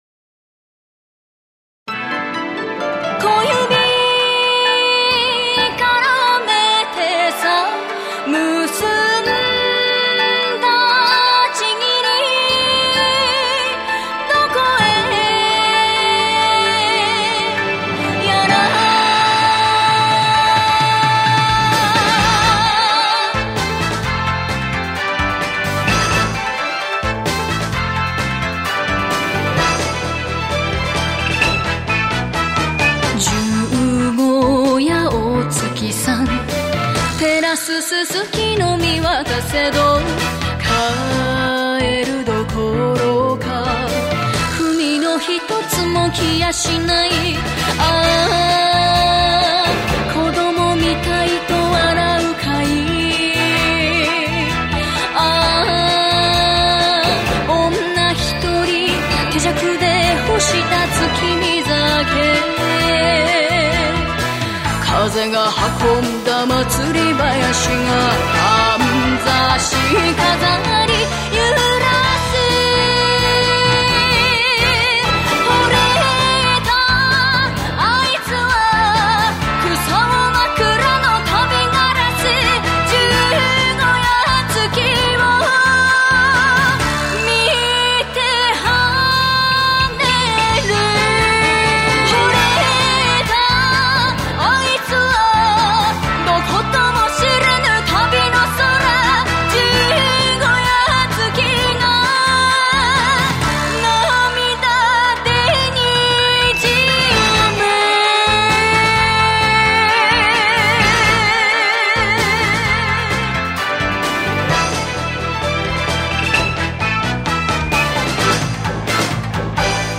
演歌系資料集